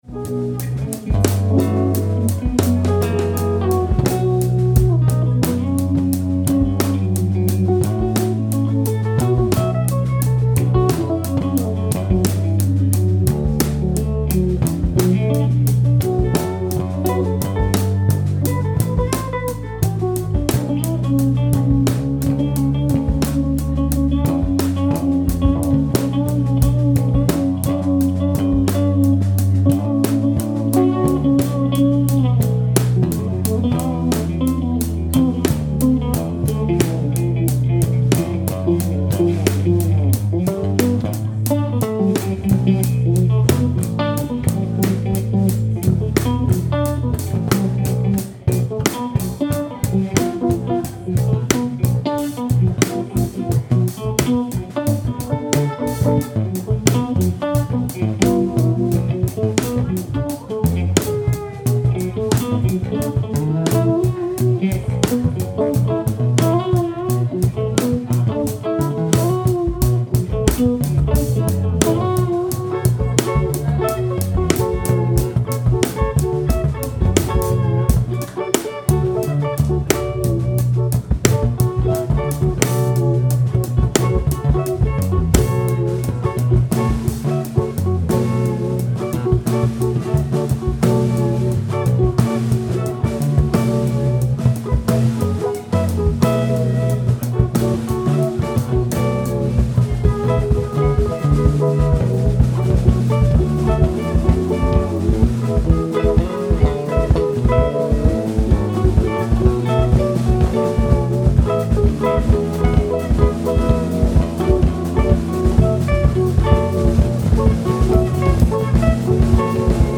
Six Sided Cube at Beats and Bohos
Here are some short clips from our set last Friday at Beats and Bohos, for their yearly art-walk.